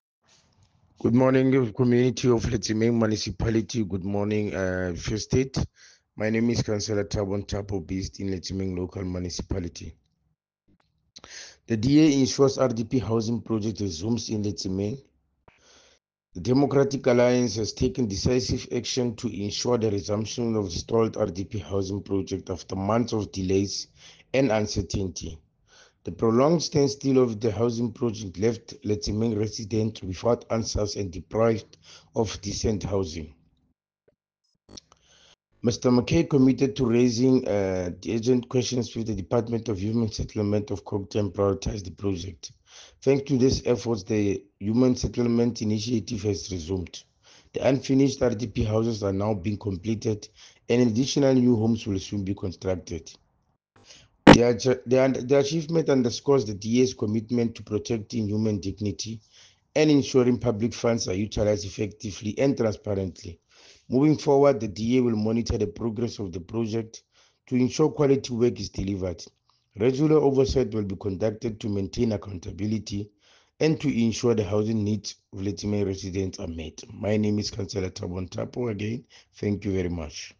English soundbite by Cllr Thabo Nthapo and